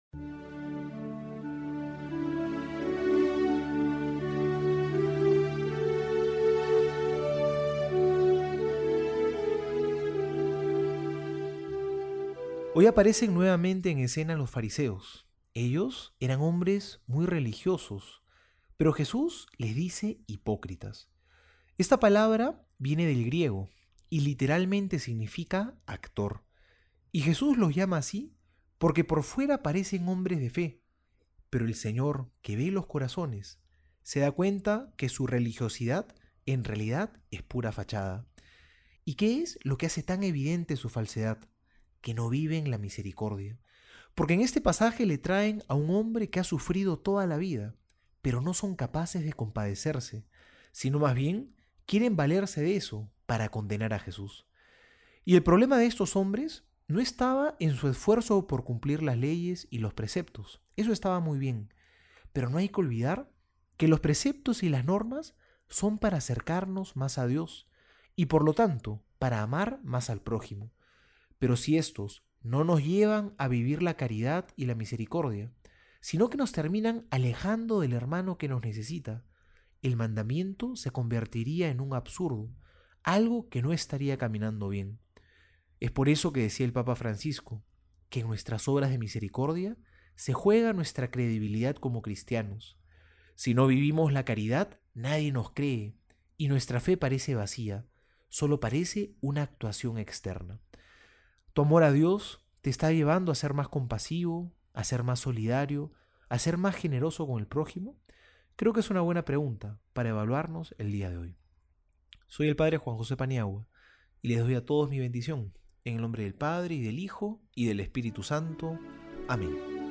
Homilía para hoy:
Lunes homilia Lucas 6 6-11.mp3